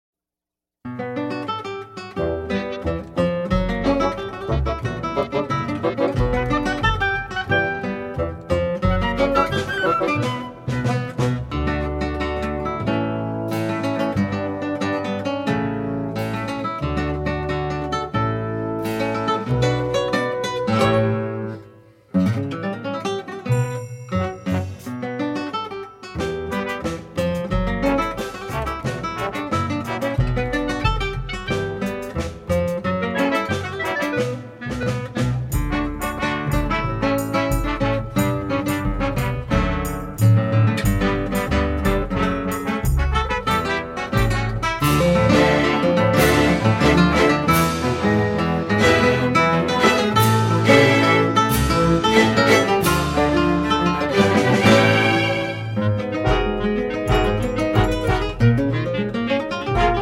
Classical Contemporary Music for guitar and orchestra
guitar